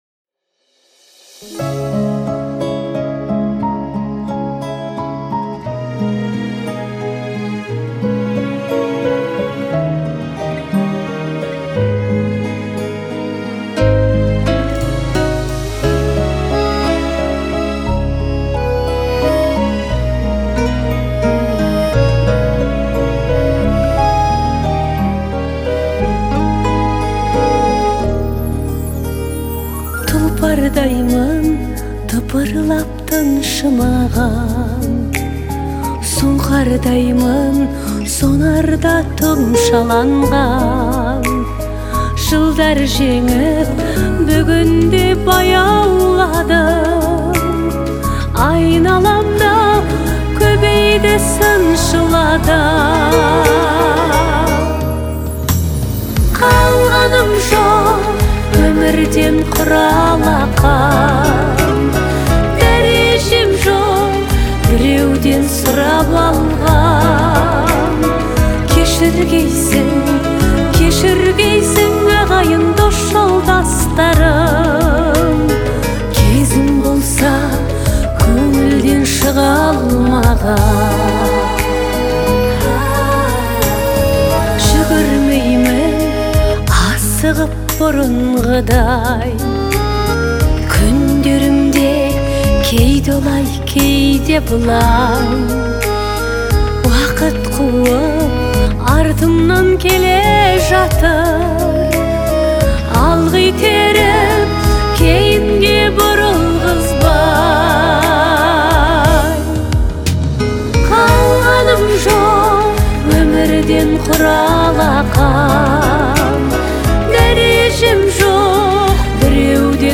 песня в жанре казахской поп-музыки